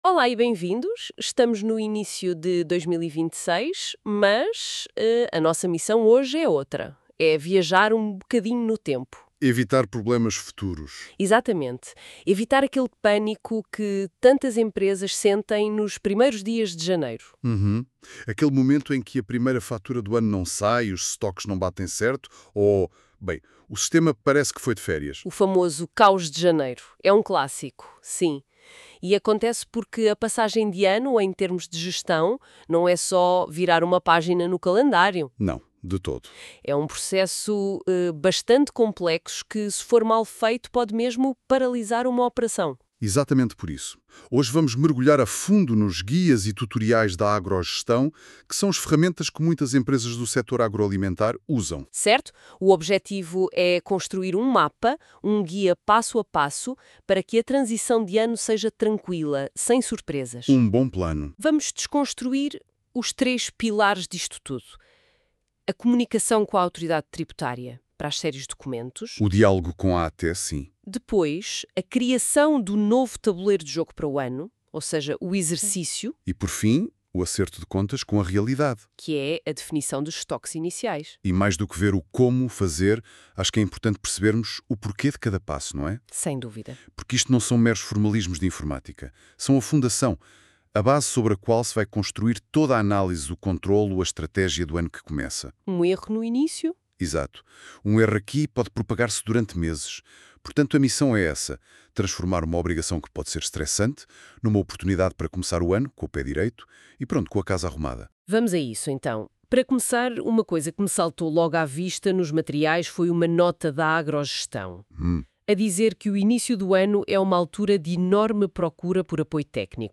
Para quem prefere consumir informação em formato áudio, disponibilizamos também um resumo explicativo em áudio , com cerca de 15 minutos , gerado com recurso a Inteligência Artificial com base nos conteúdos dos tutoriais publicados.